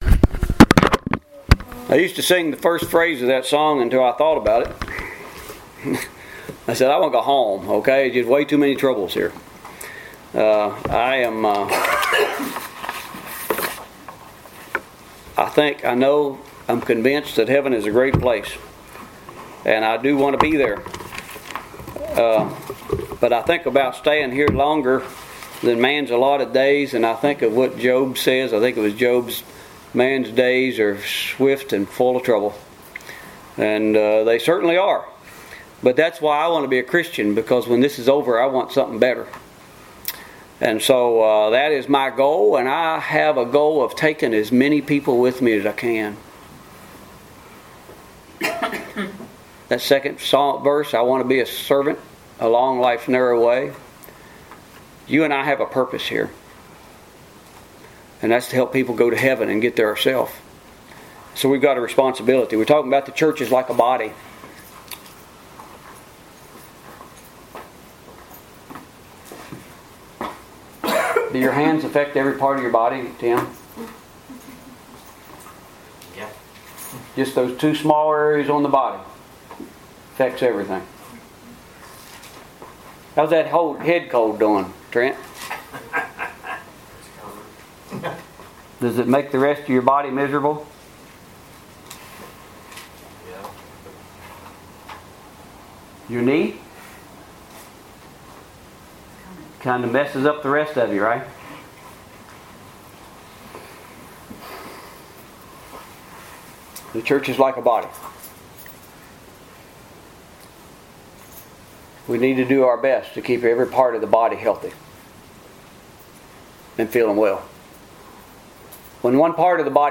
The Church is Like a Body, Part 2 Bible , church , scripture , sermon , talents Post a comment Cancel Reply You must be logged in to post a comment.